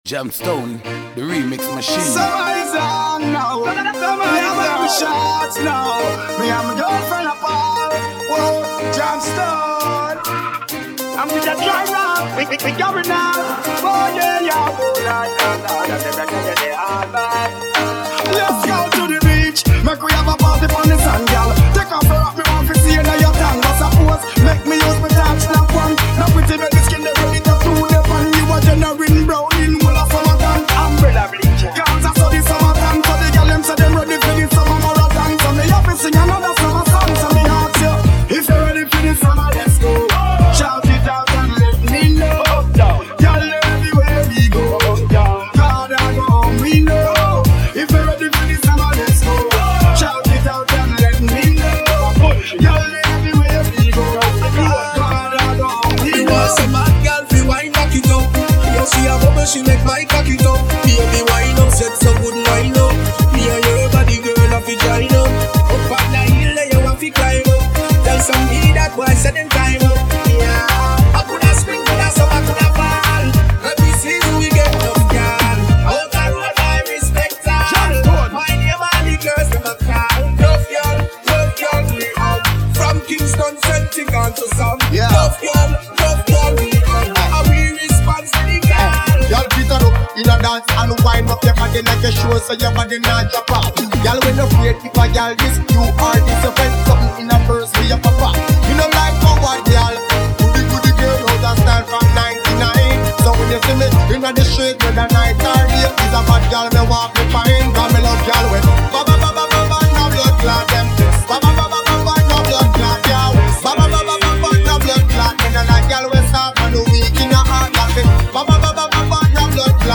Vocal tracks